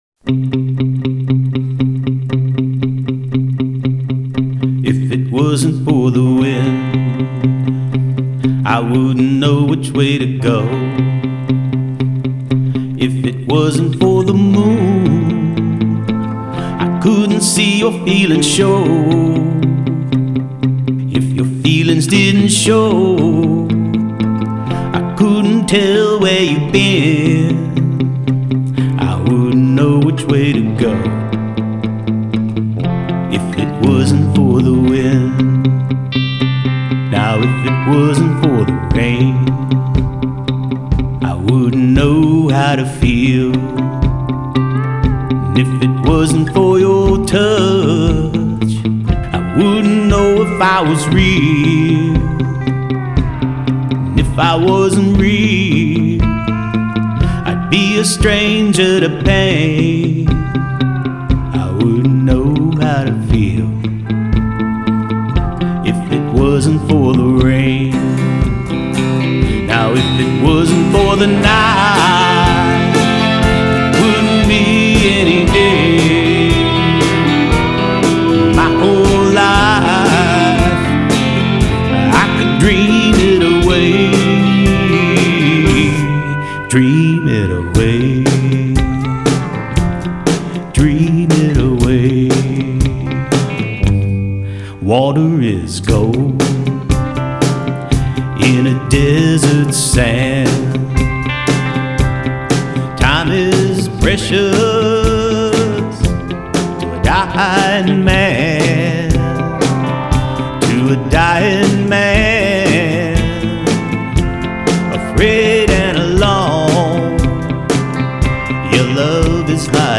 vocals, Telecaster guitar